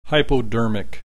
click this icon to hear the preceding term pronounced needle